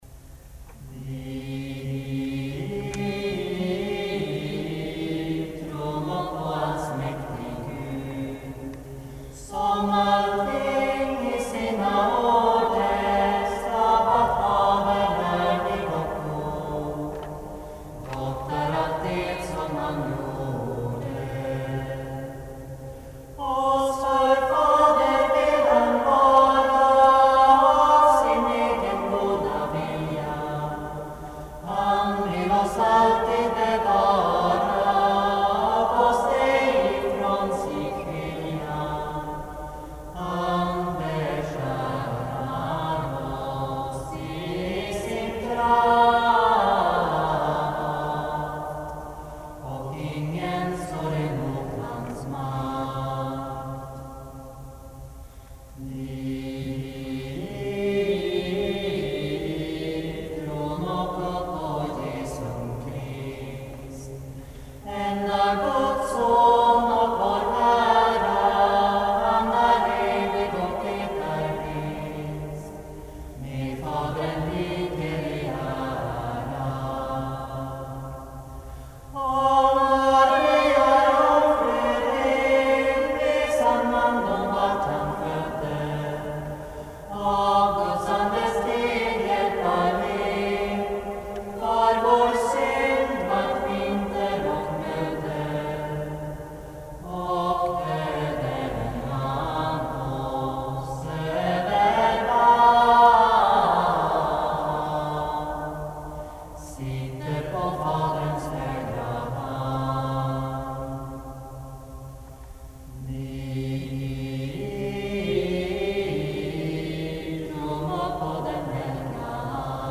Medeltida mp3:or
Uppsala domkyrka 2003: